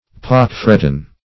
Pock-fretten \Pock"-fret`ten\, a. See Pockmarked .